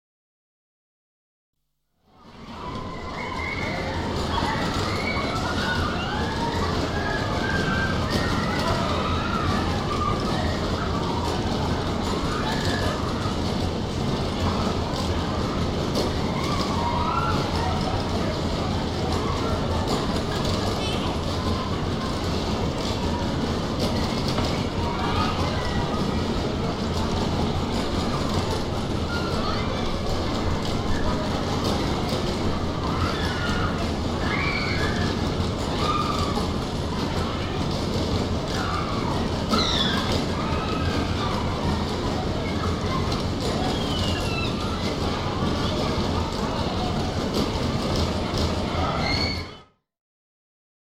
دانلود صدای مردم در شهربازی و بازی کردن از ساعد نیوز با لینک مستقیم و کیفیت بالا
جلوه های صوتی